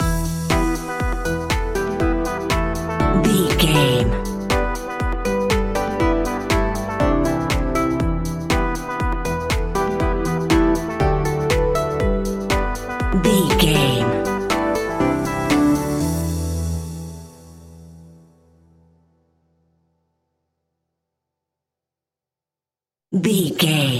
Aeolian/Minor
hopeful
synthesiser
drum machine
electric piano
acoustic guitar
electronic
instrumentals
synth bass